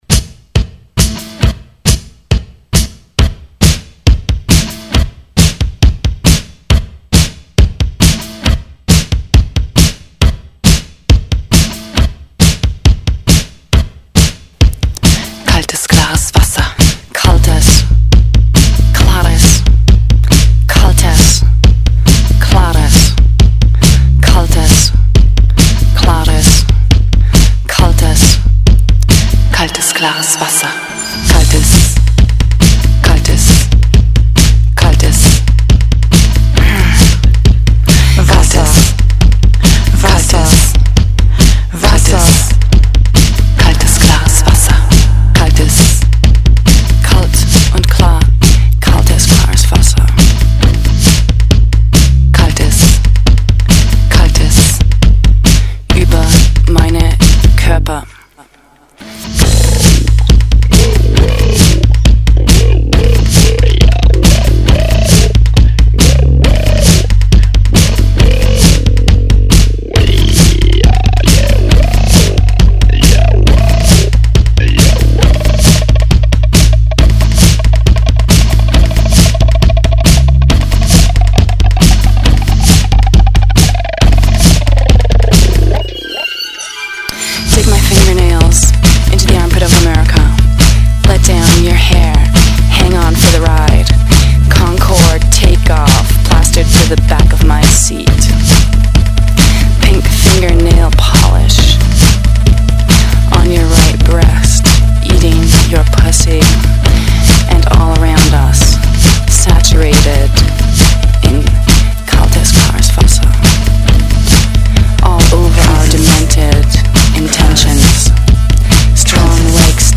Indiepolice 7 electro-pop session